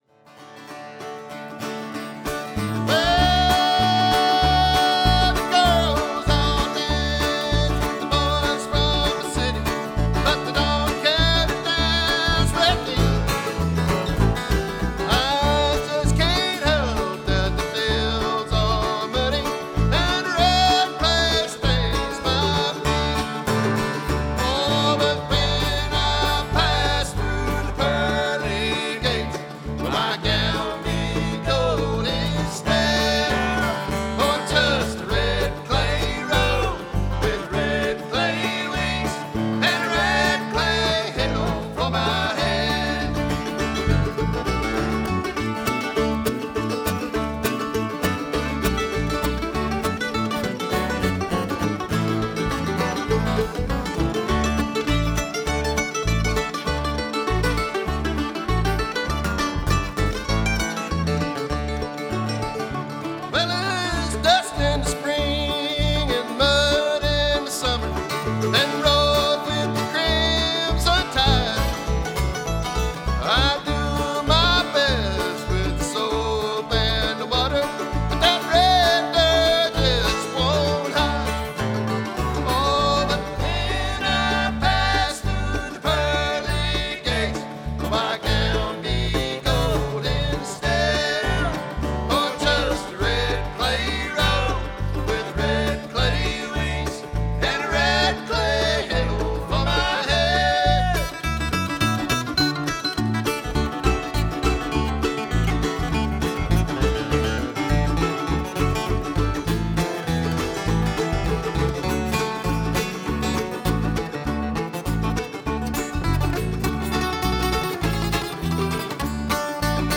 Birdfest Music Festival Pinewood, South Carolina
guitar
mandolin
electronic bass
fiddle
banjo